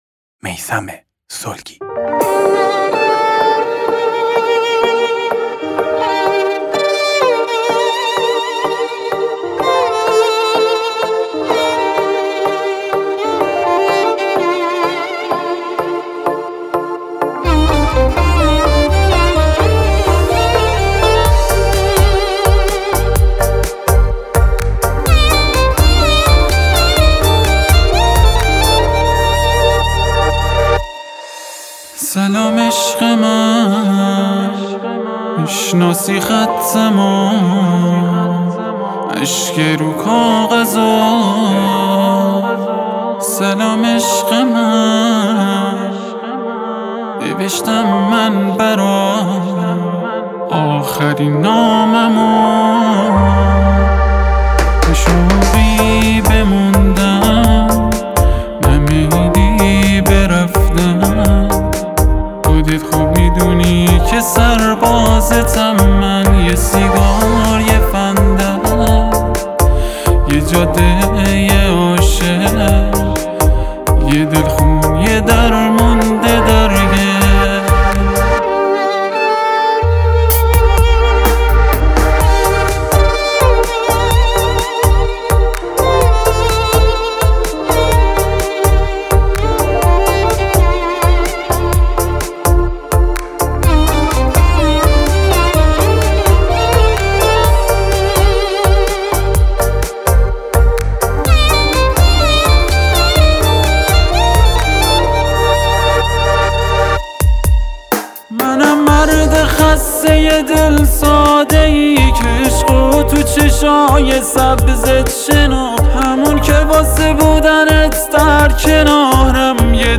آهنگ کردی و سنندجی